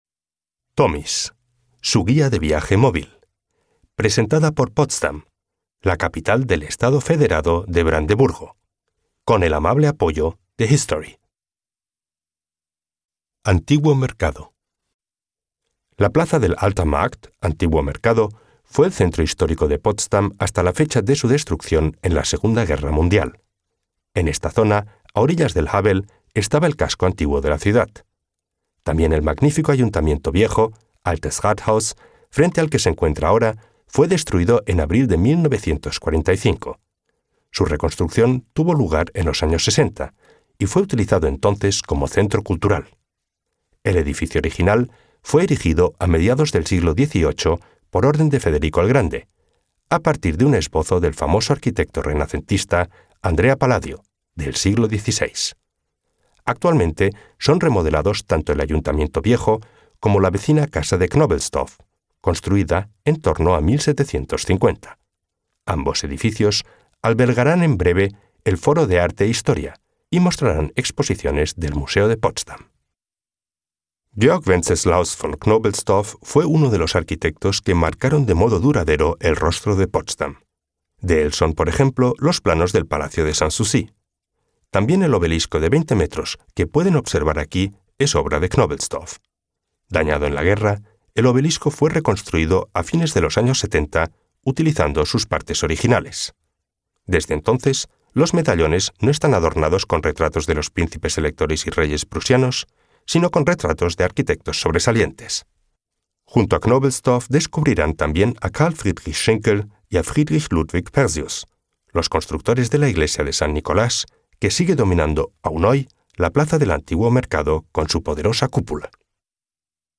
Los audioreportajes tienen una duración de dos a tres minutos.